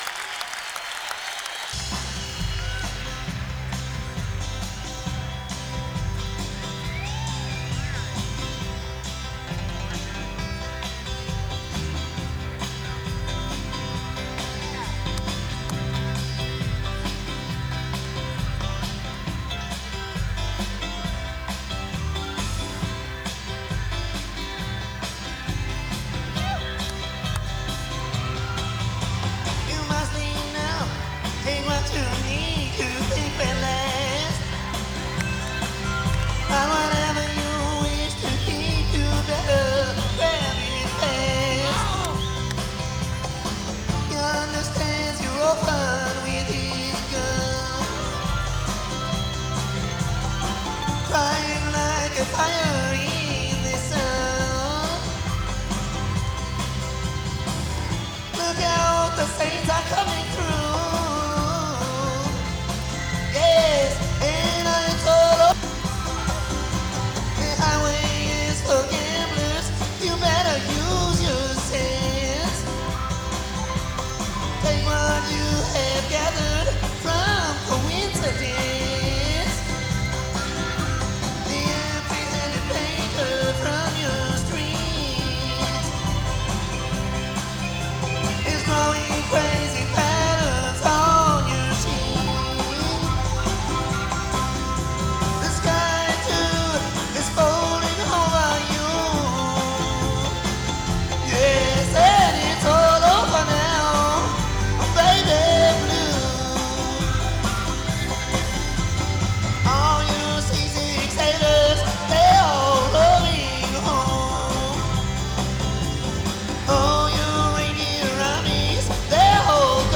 Adapted for a big band.